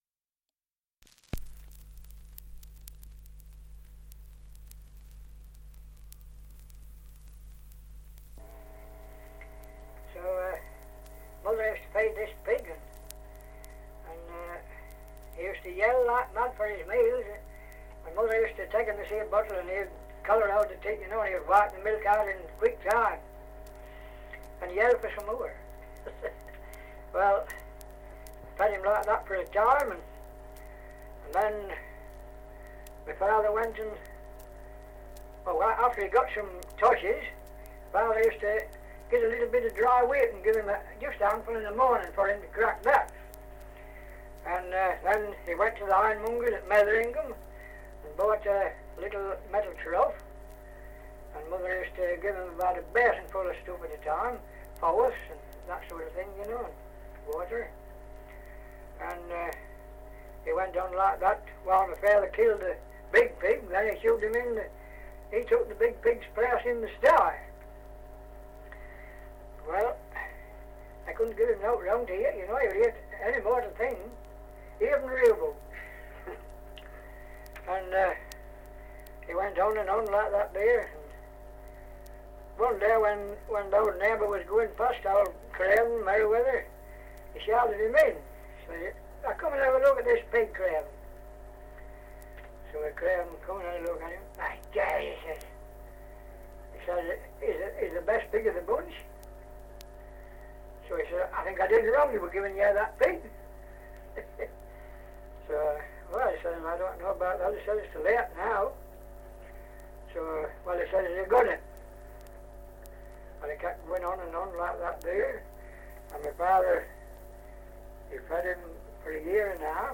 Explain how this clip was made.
Survey of English Dialects recording in Scopwick, Lincolnshire 78 r.p.m., cellulose nitrate on aluminium